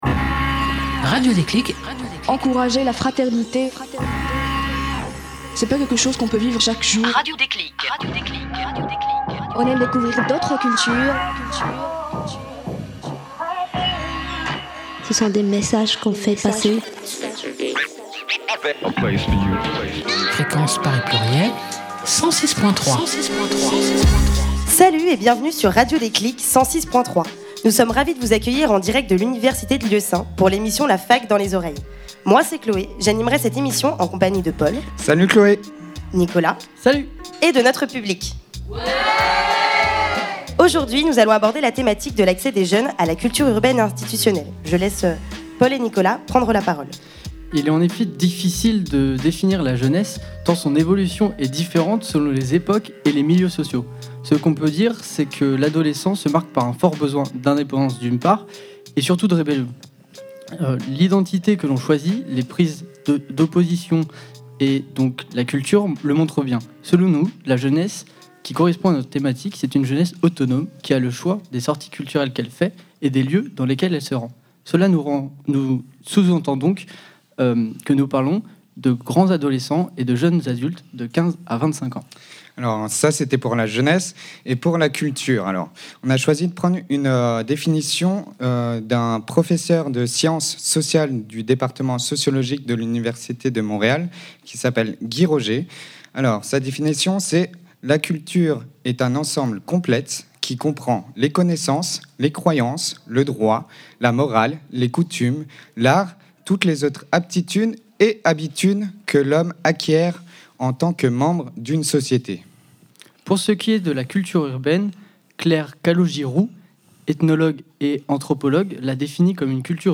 Une émission en public et en direct de l’IUT Sénart Fontainbleau
Les étudiants venus assister à l’émission ont pu poser leurs questions et débattent avec les professionnels invités